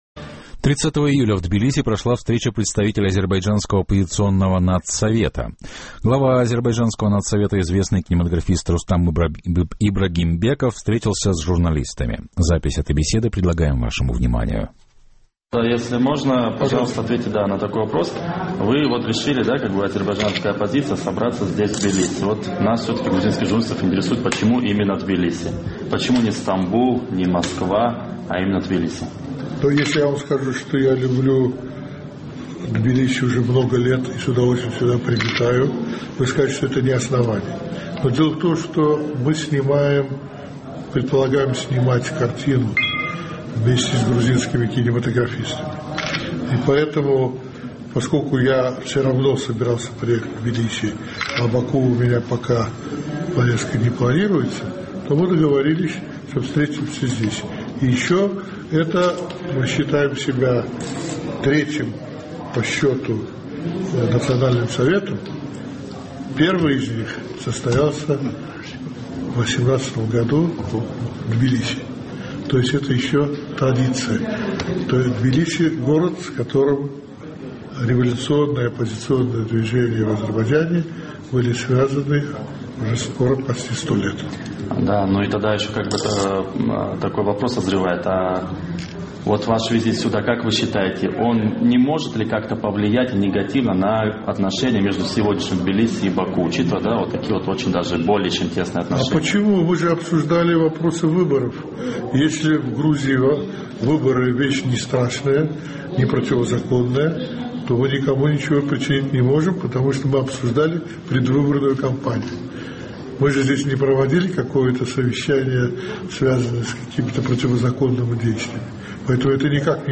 Интервью после встречи в Тбилиси представителей Национального совета Азербайджана